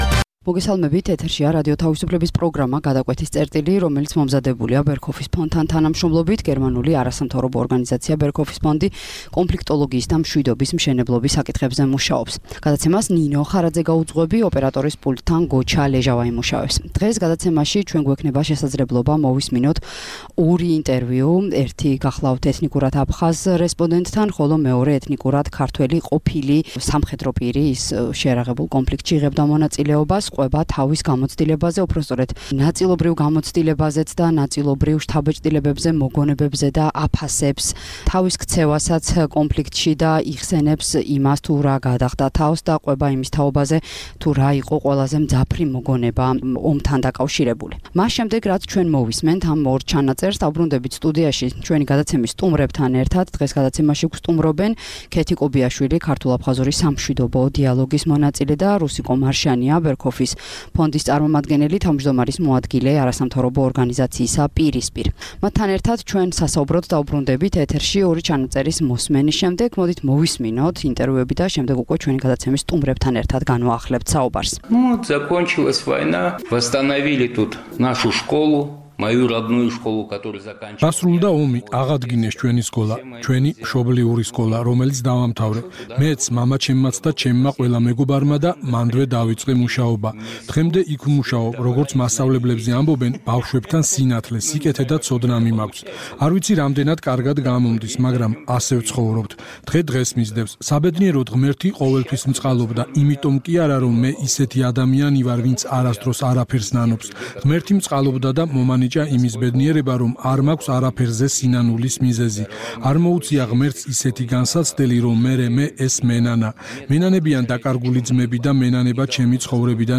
გადაცემაში მოისმენთ აფხაზეთში ომში მებრძოლი ორი ყოფილი სამხედროს მონათხრობს. ეთნიკურად აფხაზი და ქართველი მებრძოლები იხსნებენ ომში და ომის შემდეგ მათთვის ყველაზე მძაფრ შთაბეჭდილებებს და, ამის მიუხედავად, უკვე დღევანდელი გადასახედიდან მიიჩნევენ, რომ ისინი სიძულვილისგან დაცლილები არიან.